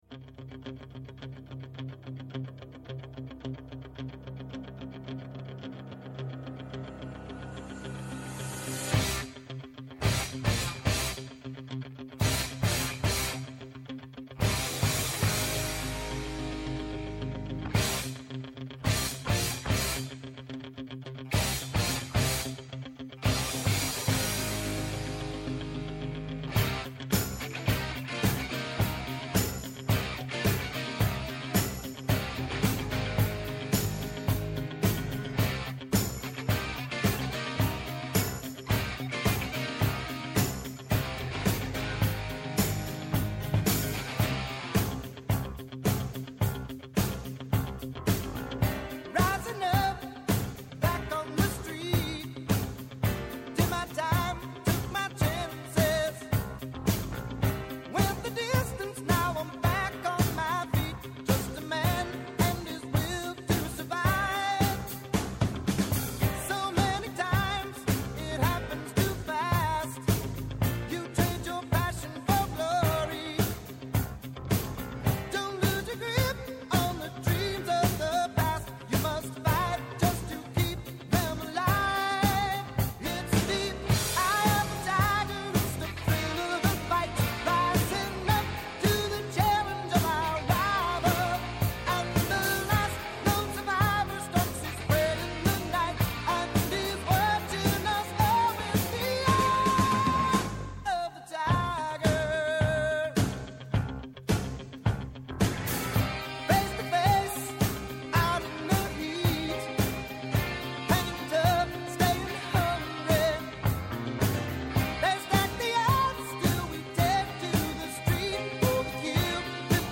Σήμερα καλεσμένος